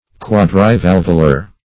\Quad`ri*val"vu*lar\